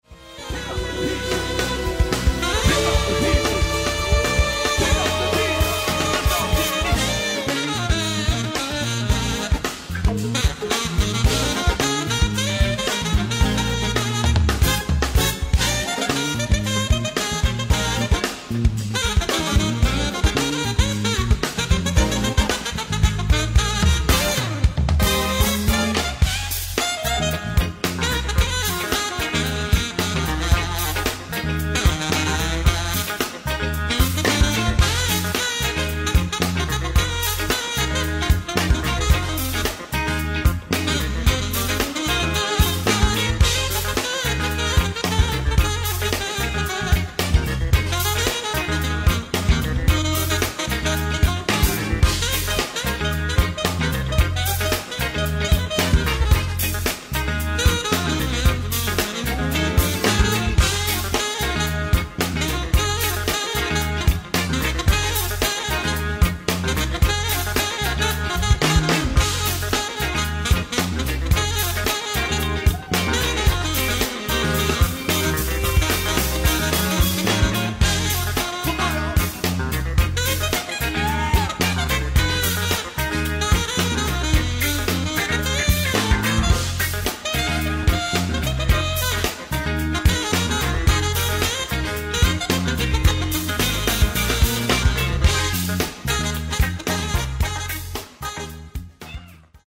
ライブ・アット・レバークーゼン、ドイツ 11/12/2002
※試聴用に実際より音質を落としています。